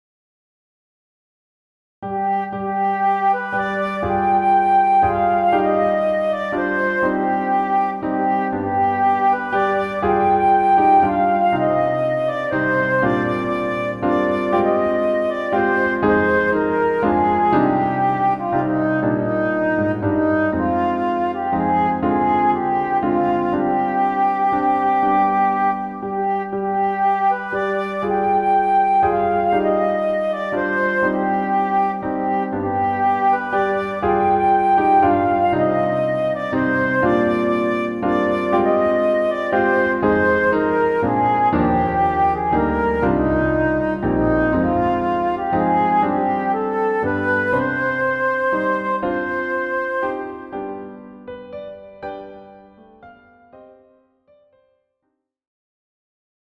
Version 1    for Clarinet
Key: C minor (solo part in D minor)